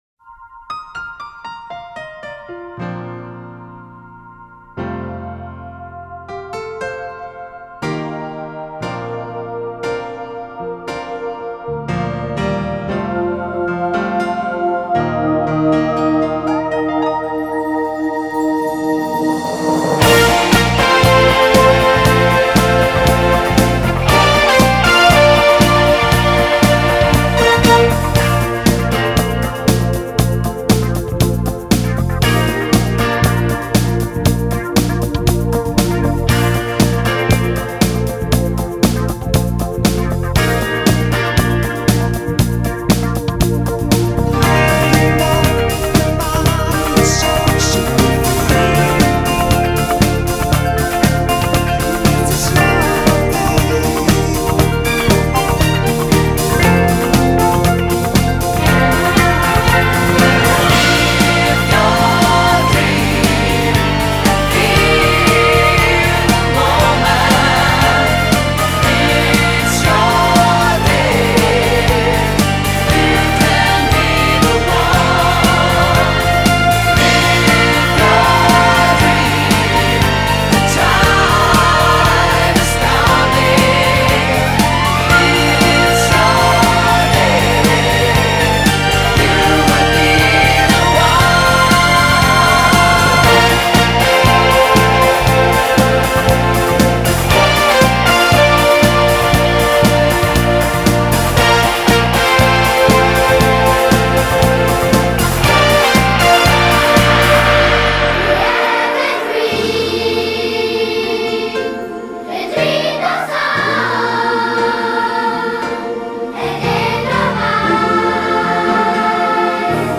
🎤 Produktion im Horus Sound Studio Hannover